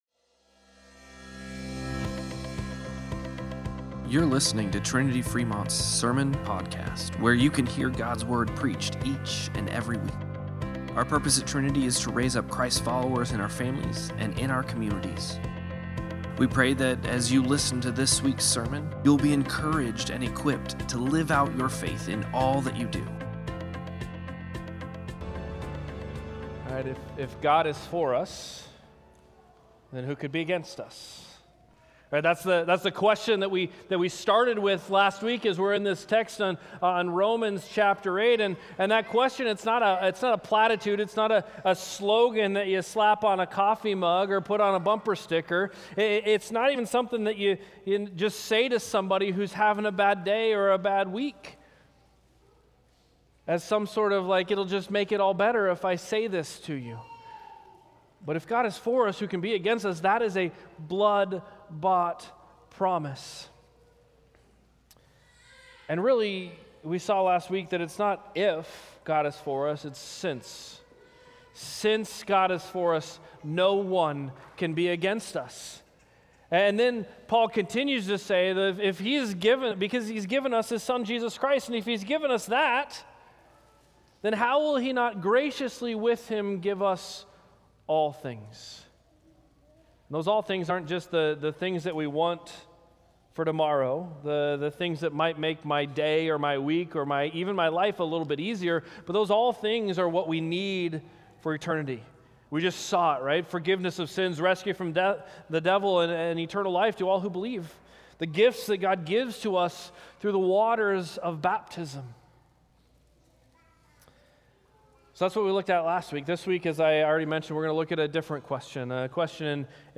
07-20-Sermon-Podcast.mp3